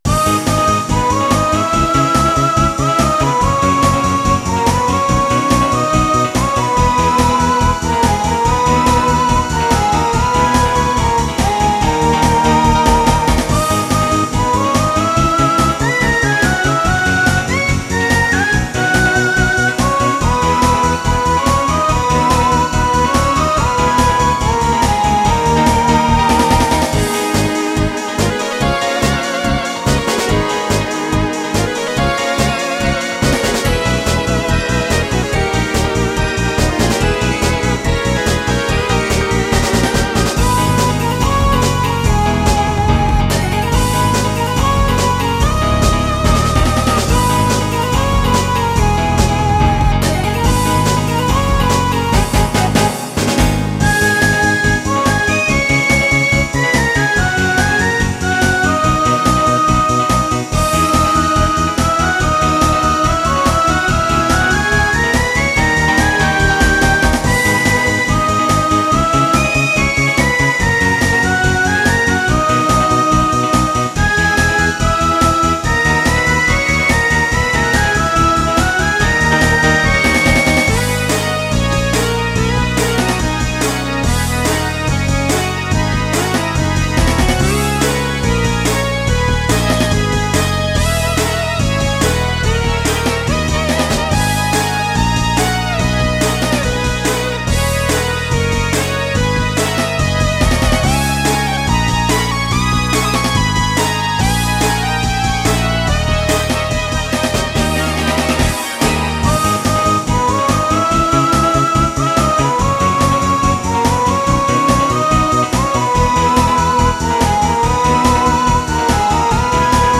MIDIアレンジ
使用音源は、お古いDTM『SC-8850/SC-88Pro』の２台を使って作曲しています。
★プロトタイプ版 For SC-8850/SC-88Pro